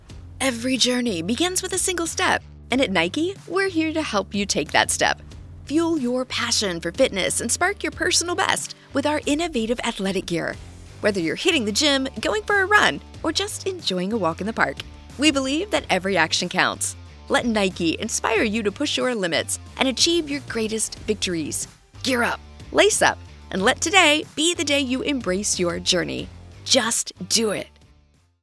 Nike - Online Ad - Energizing, Empowering, Upbeat, Driven
General American
Middle Aged